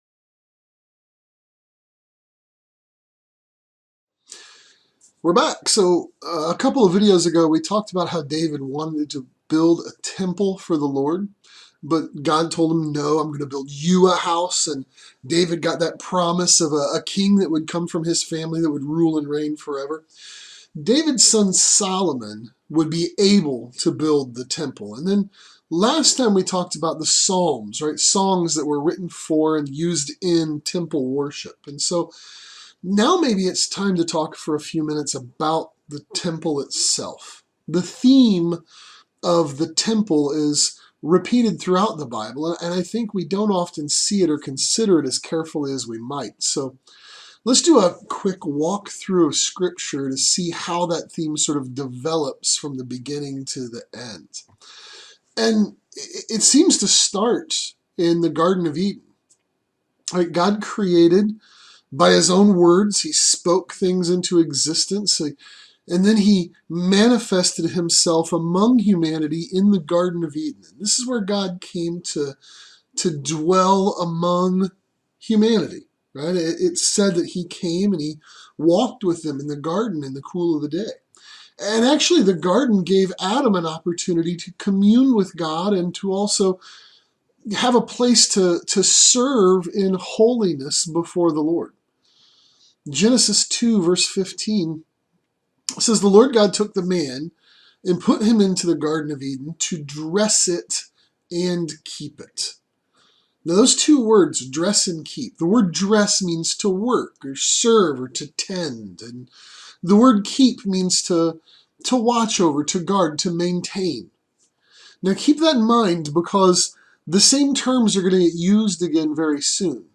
The Temple of God | SermonAudio Broadcaster is Live View the Live Stream Share this sermon Disabled by adblocker Copy URL Copied!